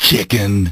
cpu_bonus_chicken.ogg